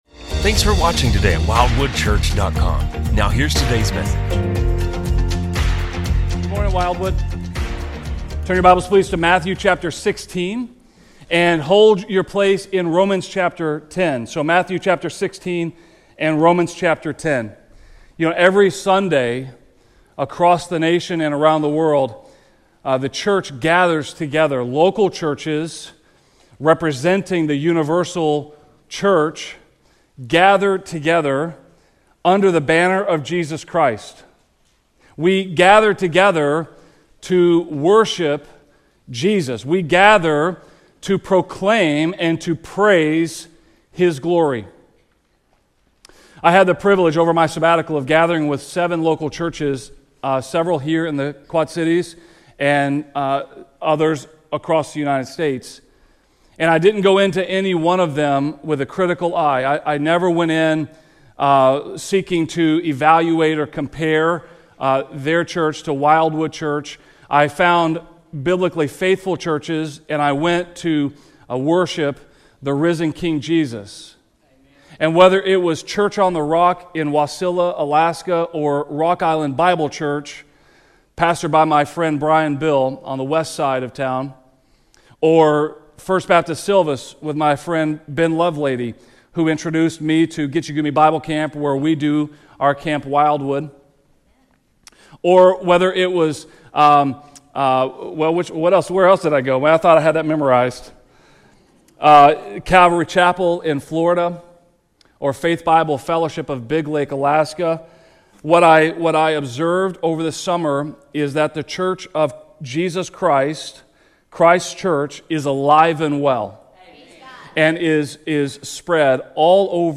A message from the series "Behold the Glory."